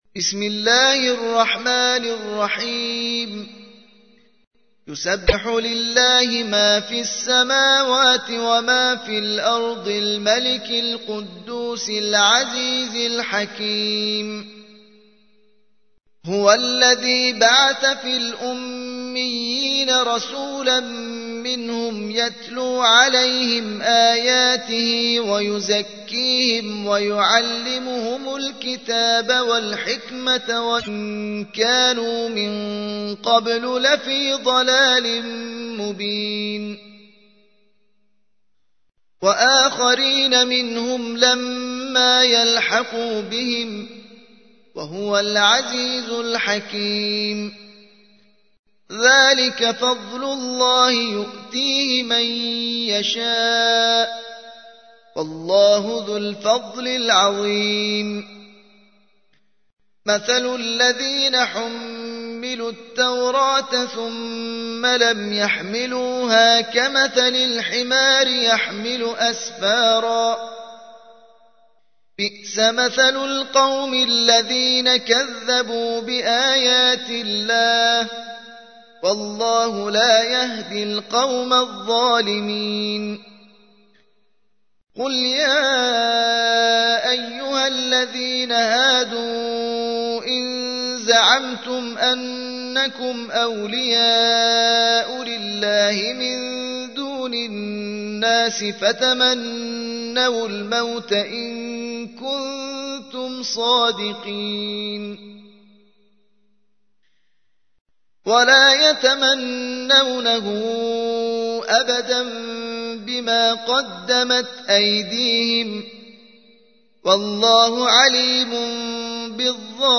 62. سورة الجمعة / القارئ